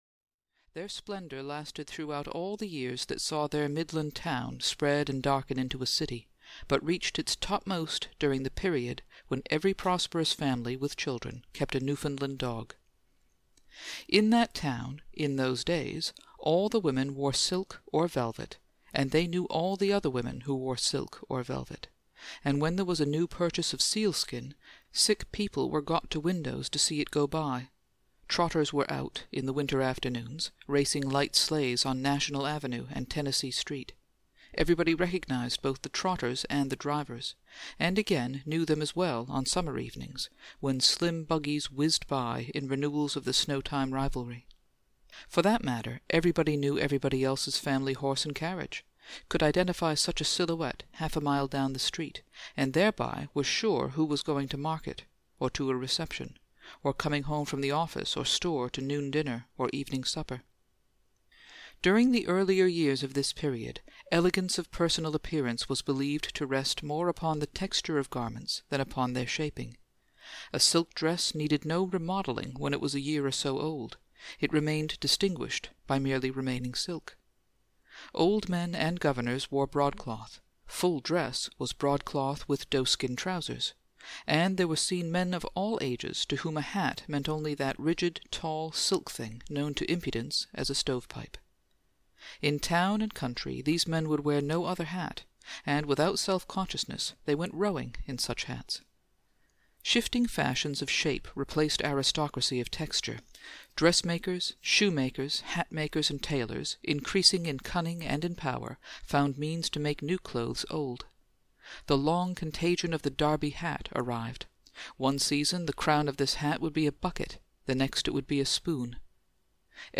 Audio knihaThe Magnificent Ambersons (EN)
Ukázka z knihy